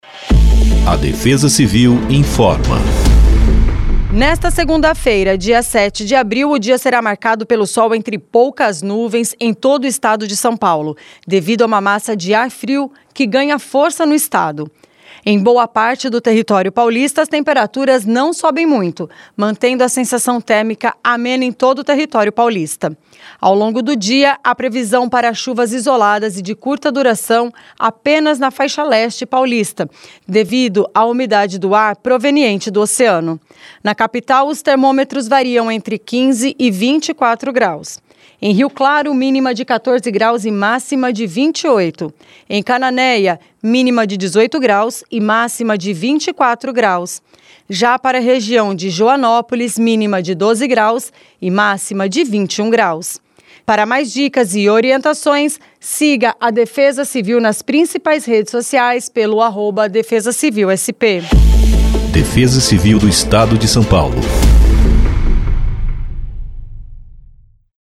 Defesa-Civil-Boletim-Previsao-do-Tempo-para-0704-Spot.mp3